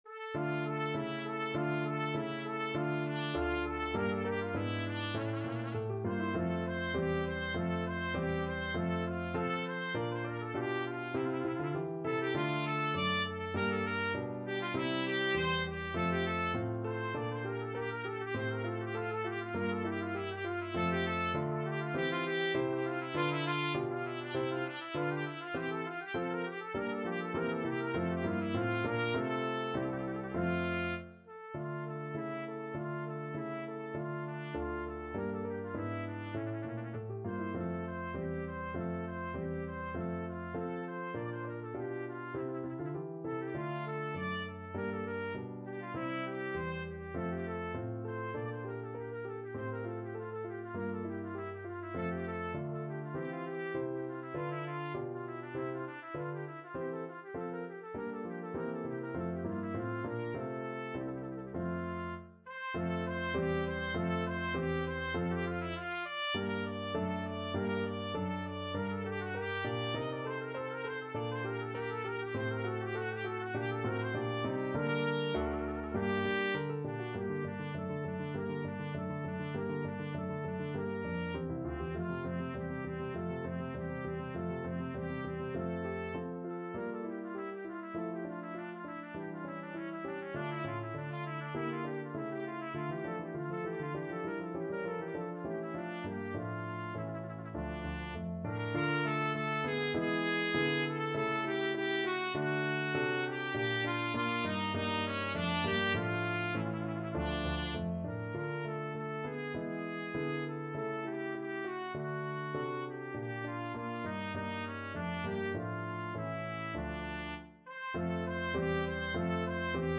4/4 (View more 4/4 Music)
D minor (Sounding Pitch) E minor (Trumpet in Bb) (View more D minor Music for Trumpet )
II: Allegro (View more music marked Allegro)
Trumpet  (View more Advanced Trumpet Music)
Classical (View more Classical Trumpet Music)